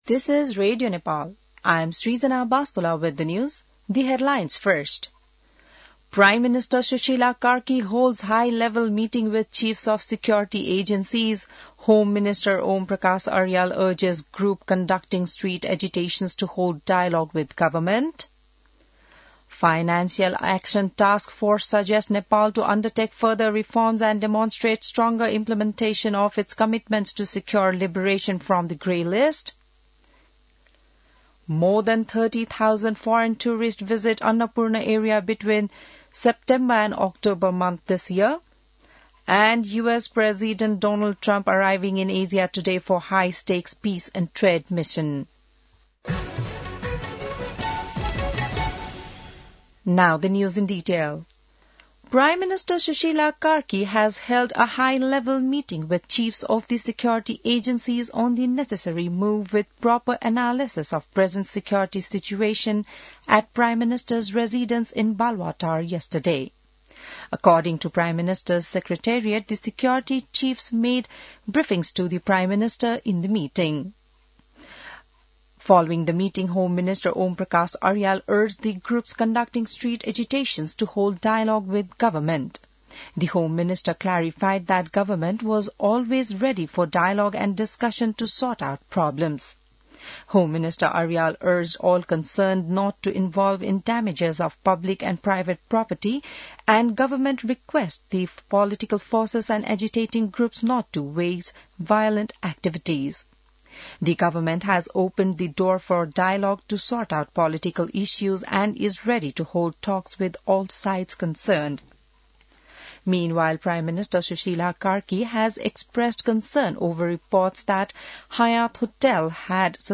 An online outlet of Nepal's national radio broadcaster
बिहान ८ बजेको अङ्ग्रेजी समाचार : १८ पुष , २०२६